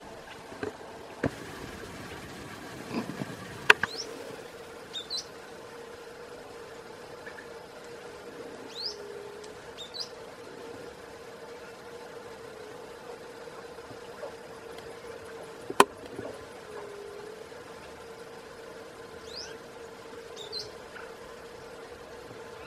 Grey-bellied Spinetail (Synallaxis cinerascens)
Life Stage: Adult
Location or protected area: Paso Centurión
Condition: Wild
Certainty: Recorded vocal